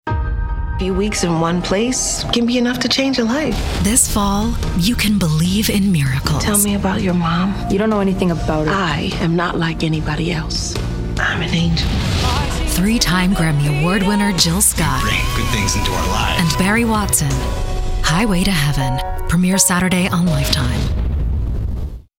Promos
I’m an American female with a vocal style offering friendly, engaging, easy listening with clear diction. Cool and bright or warm and sunny.
Sennheiser MKH 416, Neumann TLM 103.